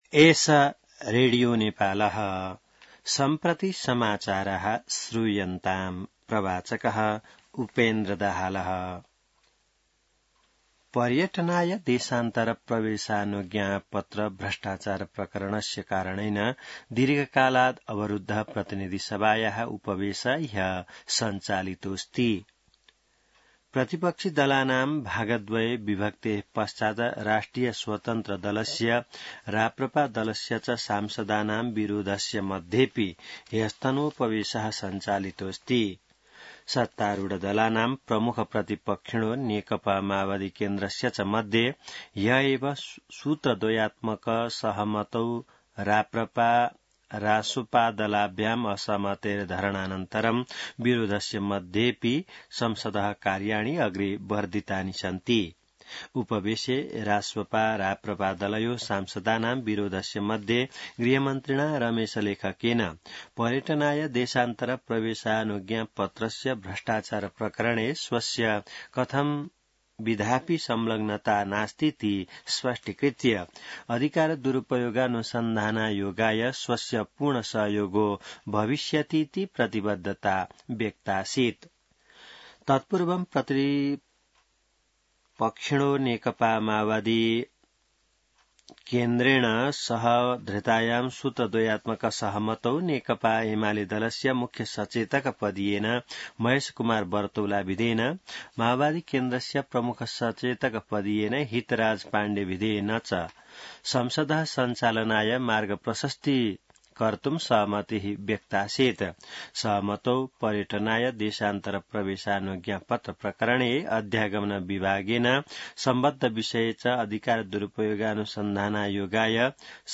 संस्कृत समाचार : ३१ जेठ , २०८२